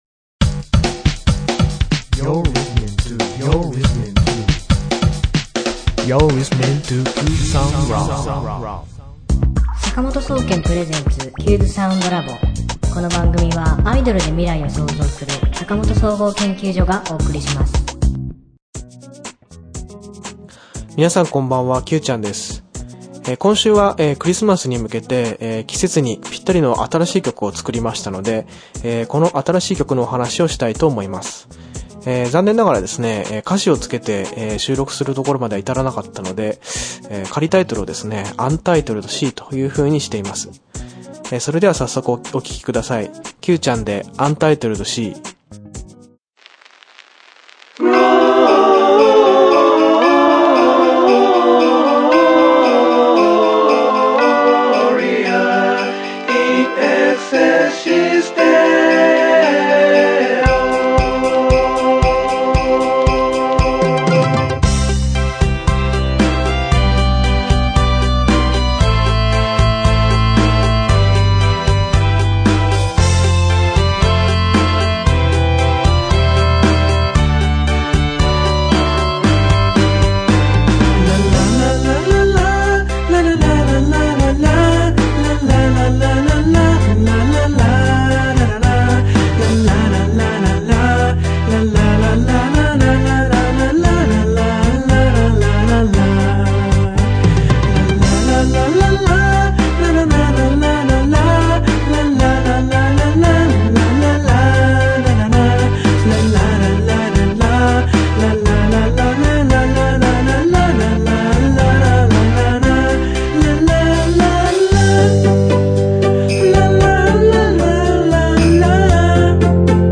今週のテーマ：新しいクリスマスソングができましたよ。 今度の新曲は季節に合わせてクリスマスソング。賛美歌を取り混ぜたサウンドは耳に心地よい仕上がりになっています。